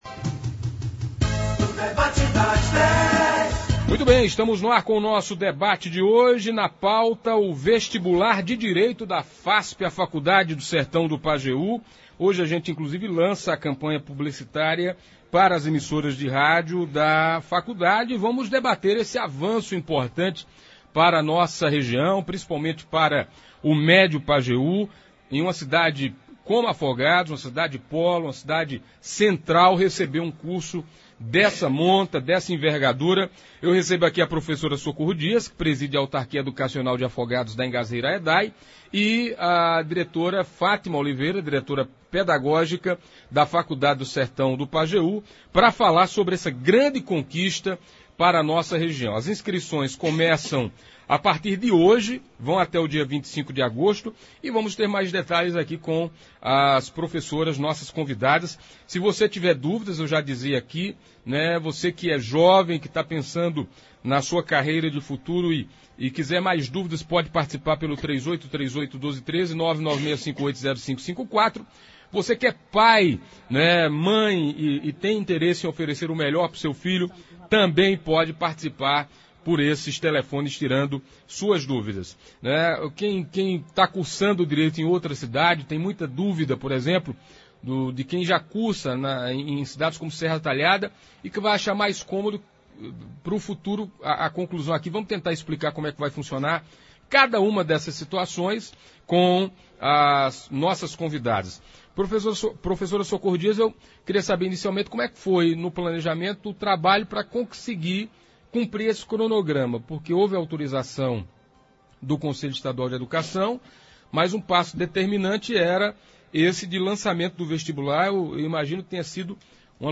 Clique aqui e leia o edital na íntegra Ouça abaixo na íntegra como foi o debate de hoje: